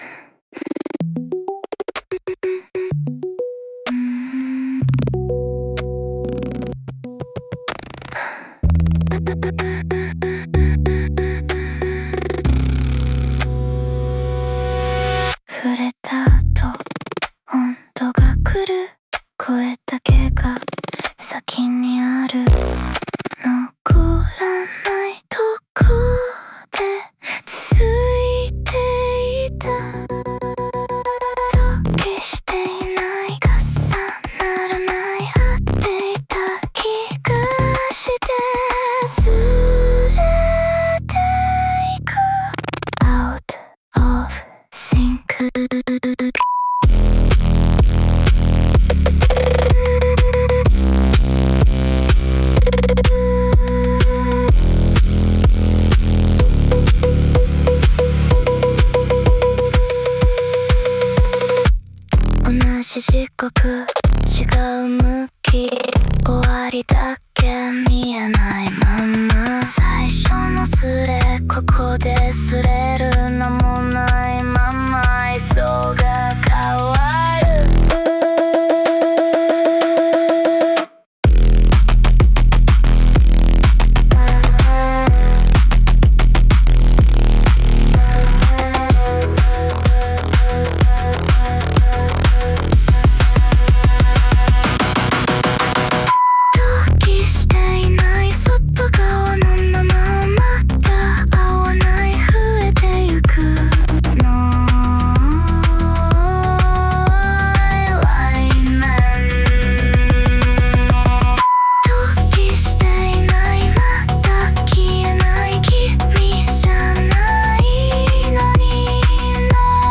女性ボーカル
イメージ：グリッチ,アンビエント,女性ボーカル,精神破壊